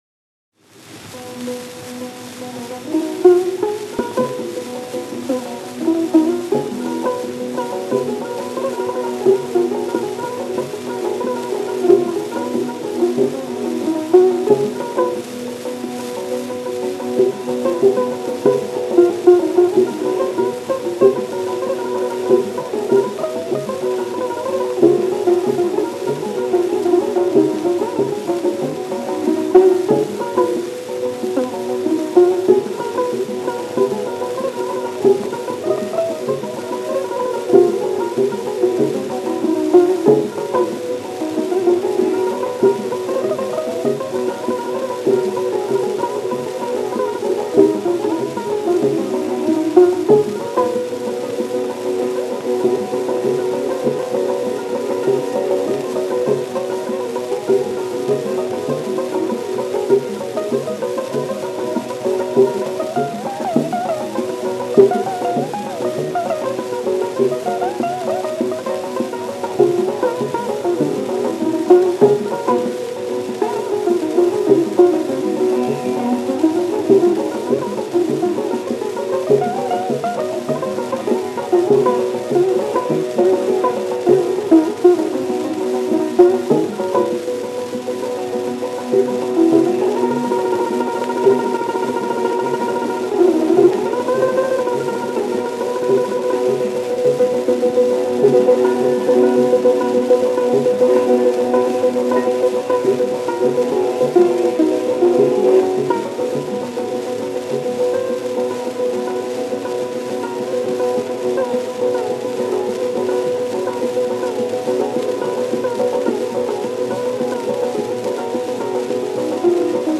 Gramophone Concert Record – 2622-h – 17364
-IMDAD KHAN – SOHANI KAWALI  –  Sitar
Imdad-Khan-SOHANI-KAWALI-SITAR-17364.mp3